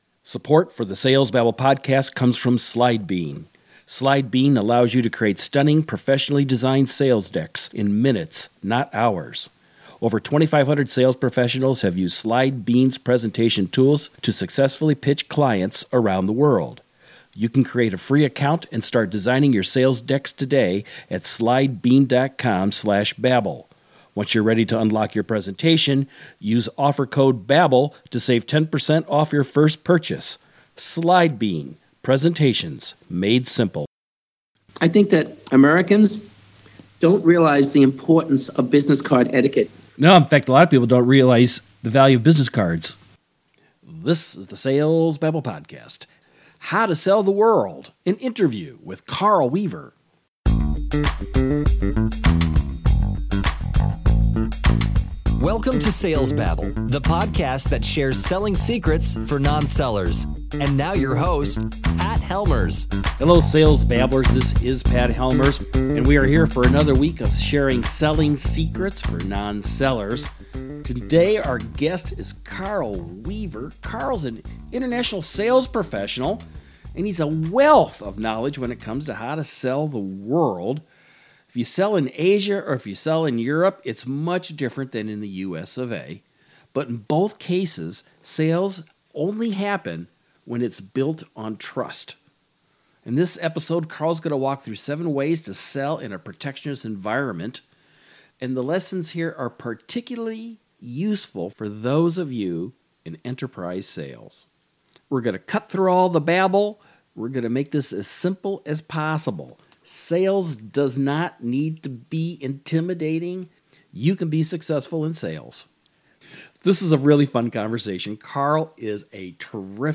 Sales Babble Interview 2017- How to Sell to the Chinese Mobile World